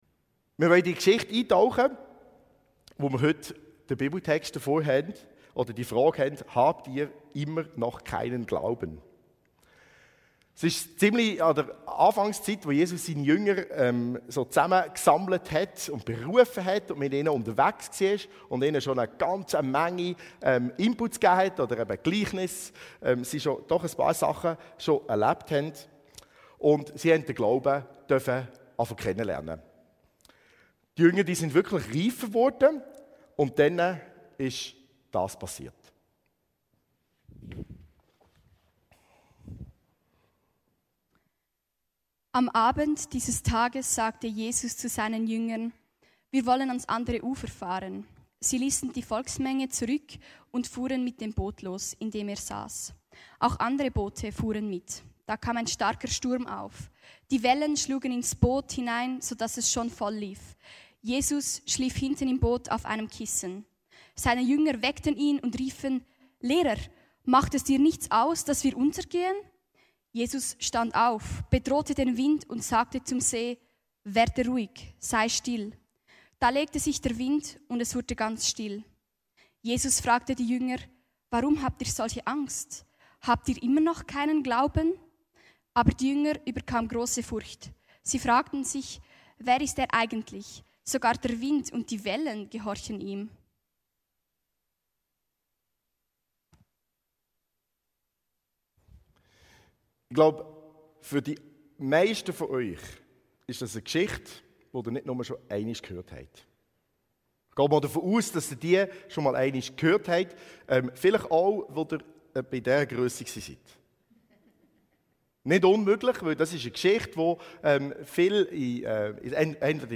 Bei dieser letzten "Frage die Jesus stellte", dem Jahresthema des Alive-Gottesdienstes im 2024, werden die Jünger wirklich herausgefordert.